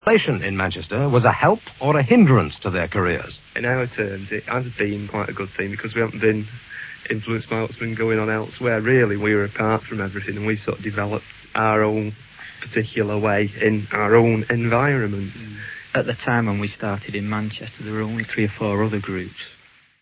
Ian Curtis & Stephen Morris Interviewed by Richard Skinner